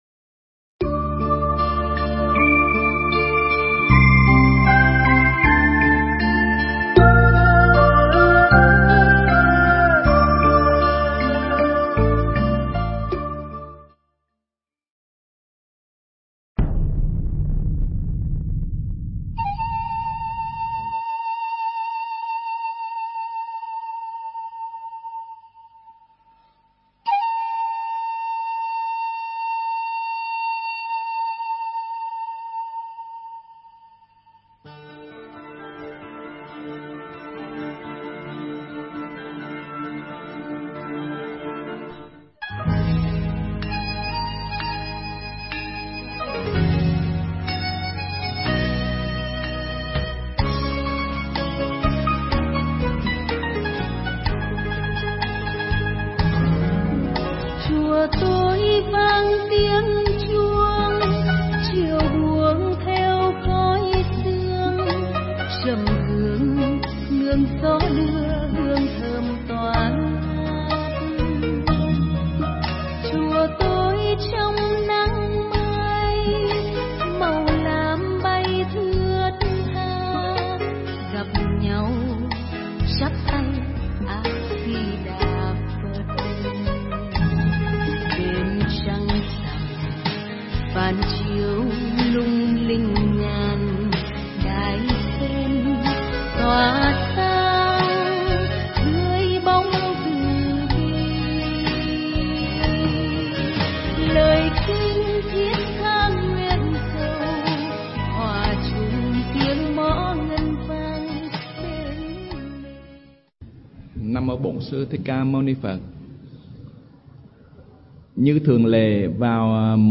Nghe Mp3 thuyết pháp Ánh Trăng Đẹp